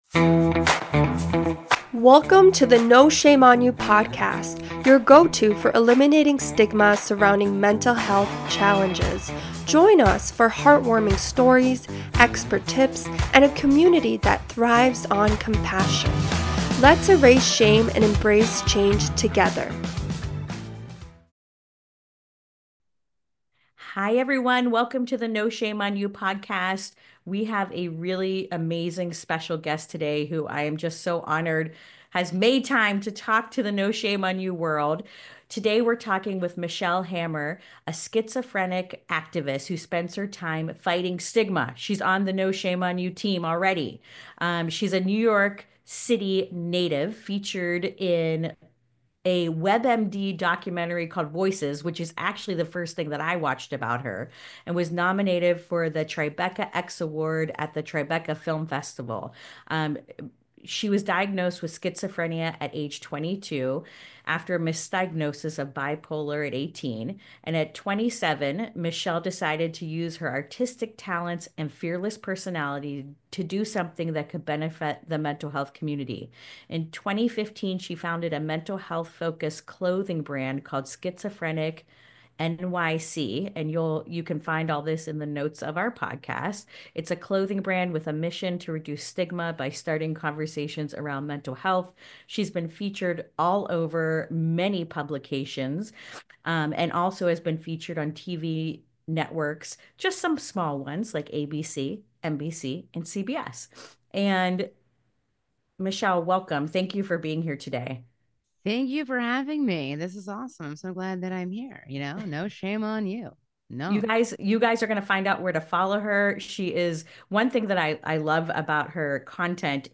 Join us for an inspiring conversation